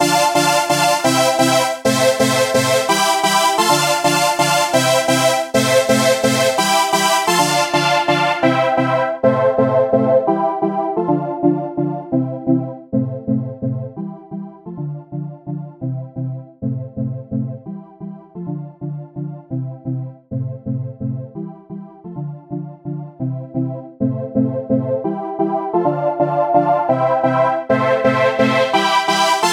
描述：只是大D/FC/EA/CG/E
标签： 130 bpm Deep House Loops Synth Loops 4.97 MB wav Key : Unknown
声道立体声